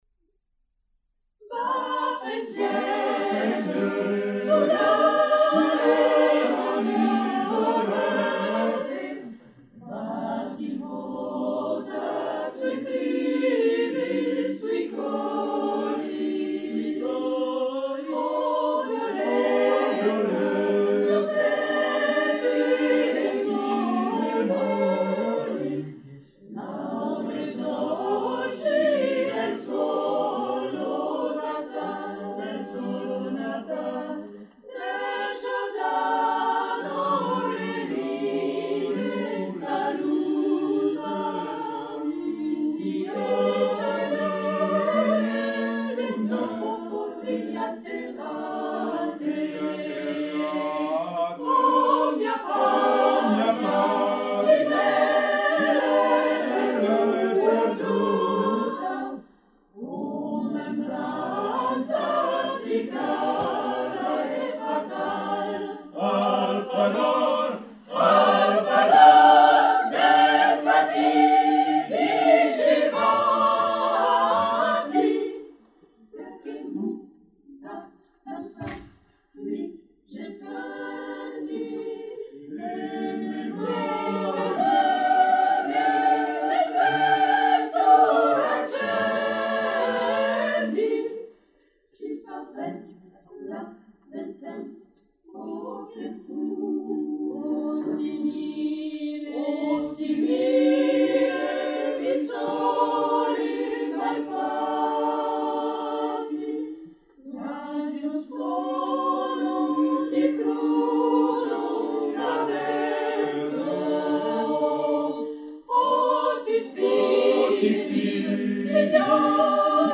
03-choeur-des-esclaves.mp3